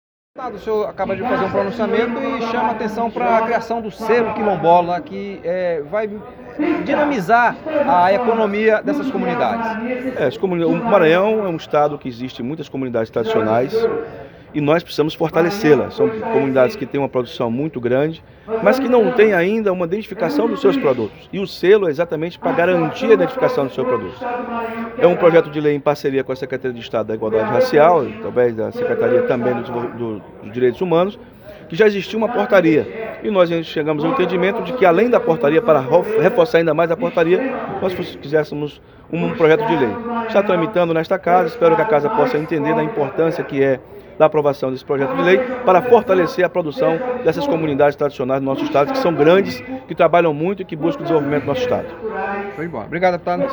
Deputado Estadual Adelmo Soares anuncia o Selo Quilombola
Deputado Adelmo Soares apresentou na sessão desta quinta-feira (7) na Assembleia Legislativa projeto de lei que cria o Selo Quilombola.